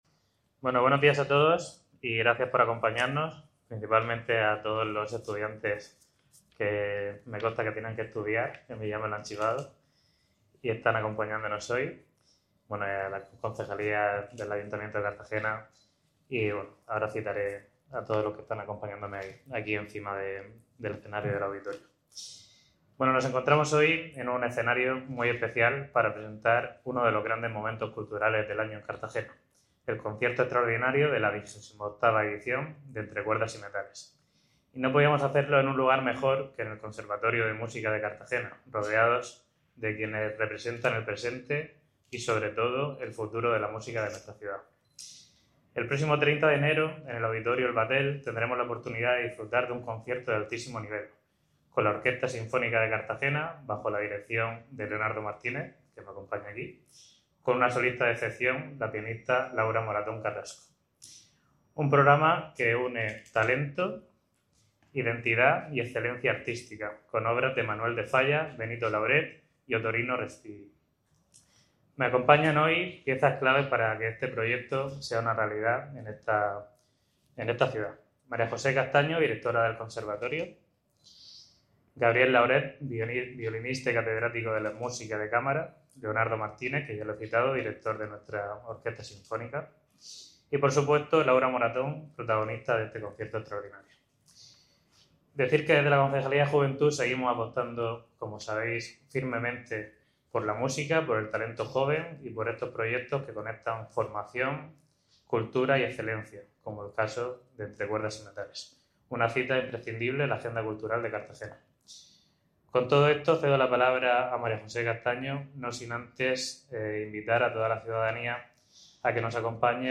Enlace a Presentación del concierto extraordinario Entre Cuerdas y Metales 2026